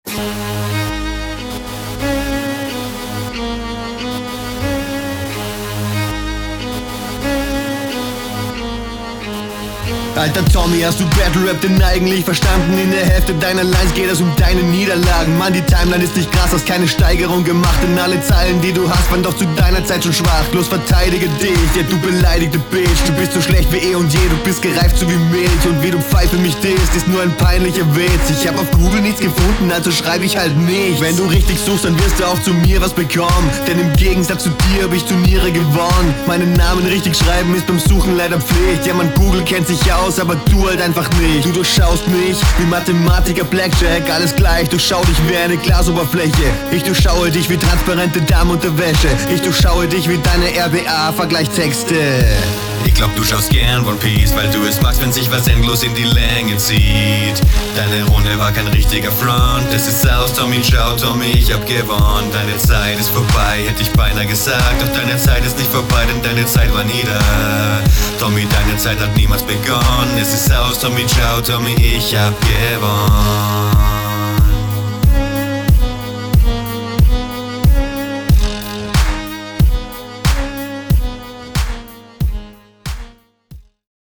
du hast gewonnen, aber sing das bitte nicht so ein :sob: